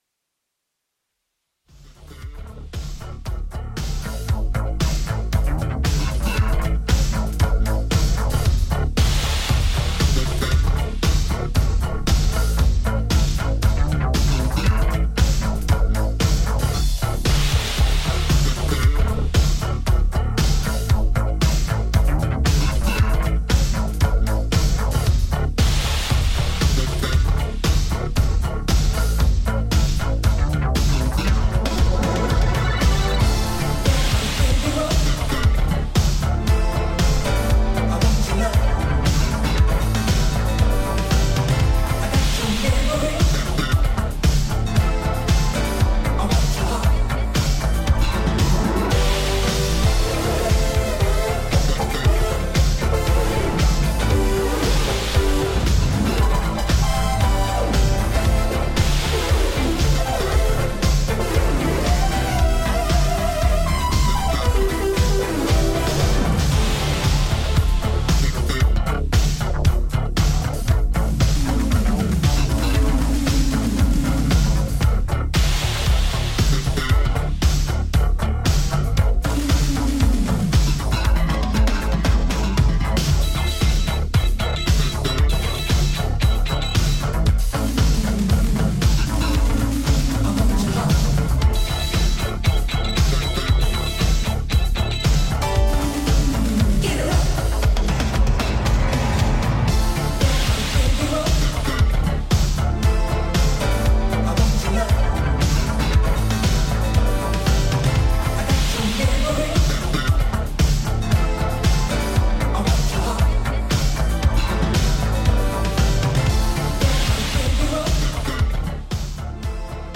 ジャンル(スタイル) NU DISCO / DISCO / RE-EDIT